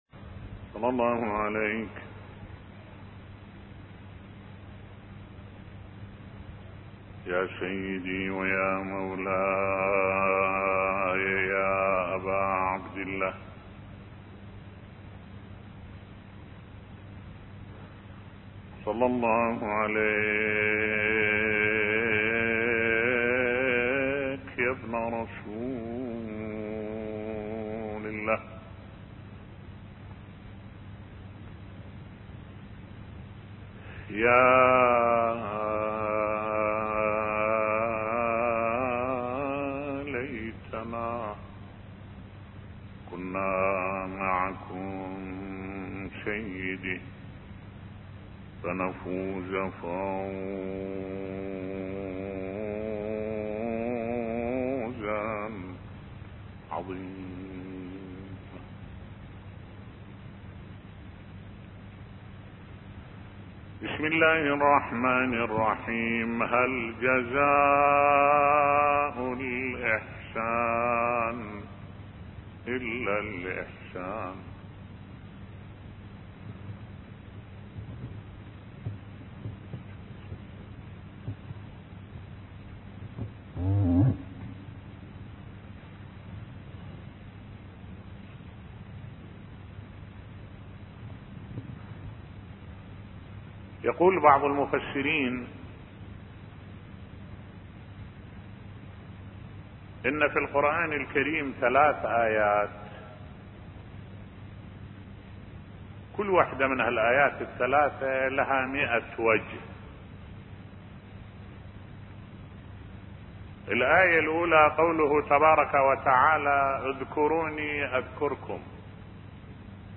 ملف صوتی هل جزاء الاحسان الا الاحسان بصوت الشيخ الدكتور أحمد الوائلي